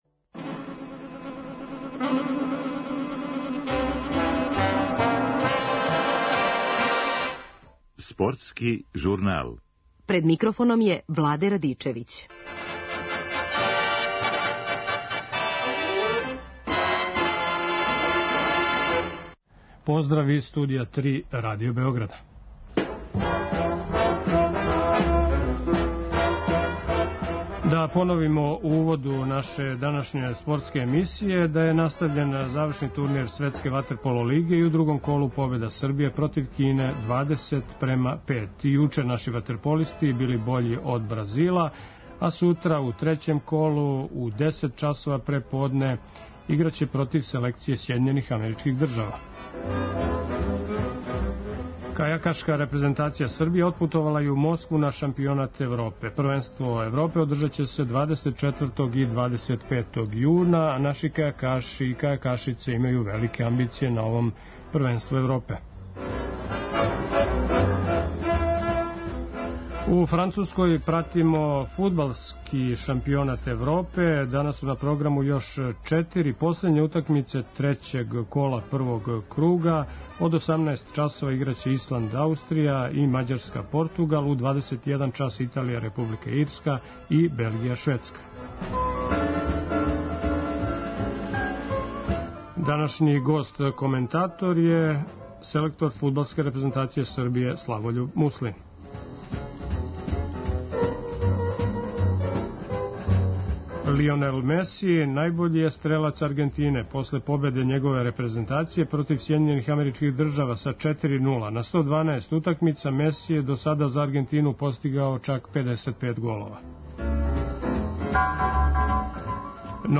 Гост Радио Београда и данашњи коментатор збивања у Француској је селектор наше репрезентације Славољуб Муслин.